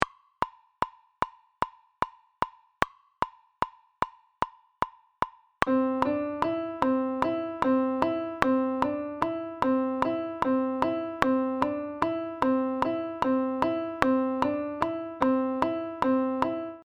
Ejemplo de compás de 7x8 con la medida de 3+2+2.
Audio de elaboración propia. Subdivisión métrica del compás 7x8 en 3 + 2 + 2. (CC BY-NC-SA)
COMPAS-7x8-1.mp3